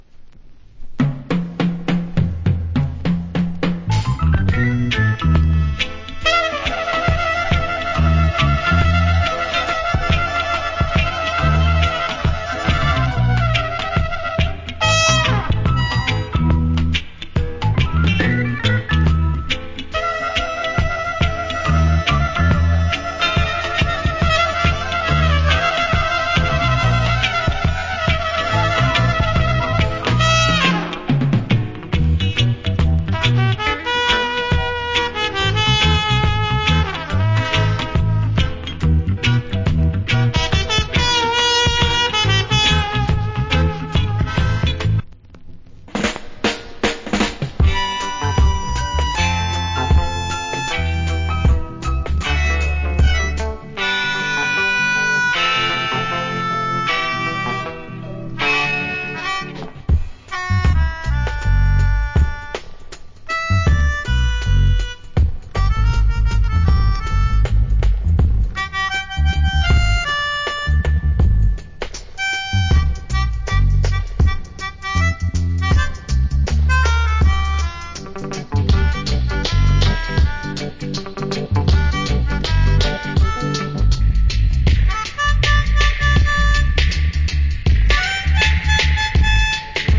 Killer Inst.